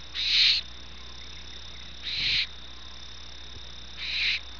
Las vocalizaciones de llamado corresponden a sonidos breves de estructura acústica simple, sin un patrón definido y compuesto de no mas de dos sílabas.
Vocalización de un Lechuza
lechuza.wav